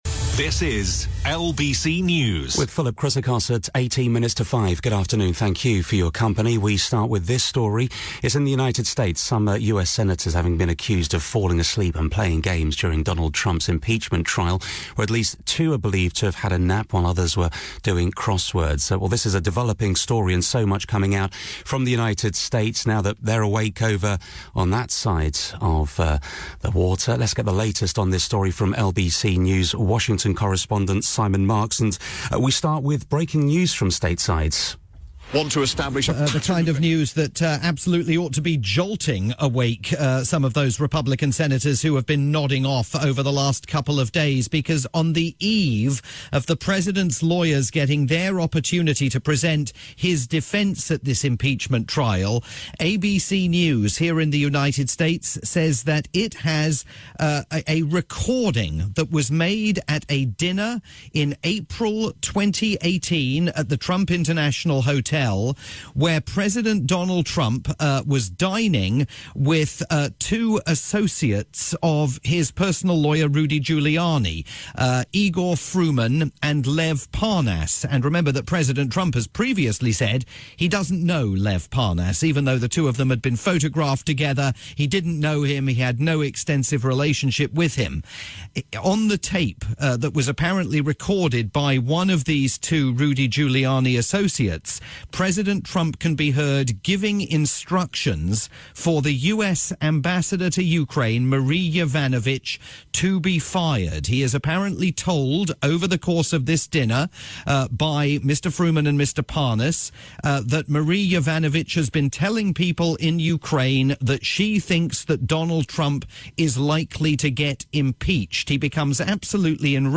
breaking news report from Washington for the UK's rolling news station LBC News